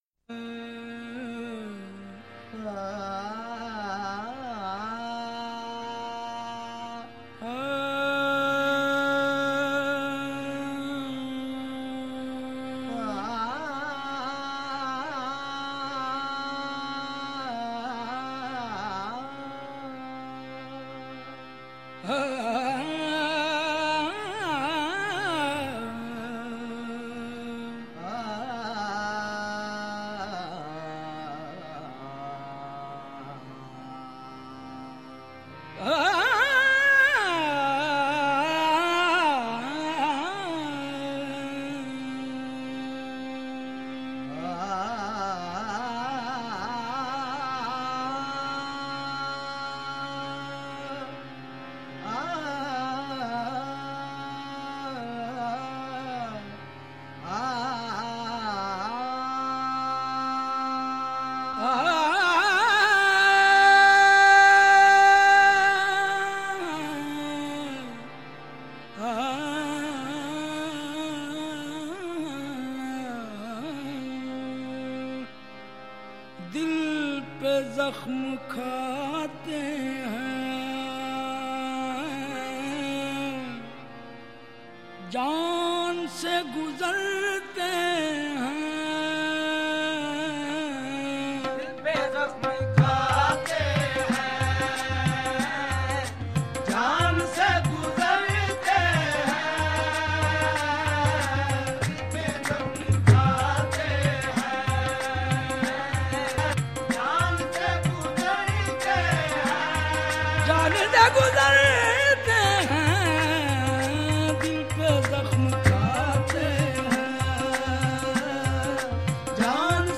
Sufi Qawwali